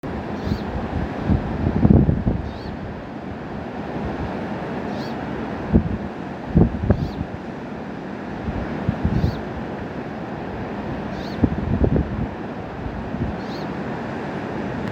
Ziemas žubīte - Novērojuma dati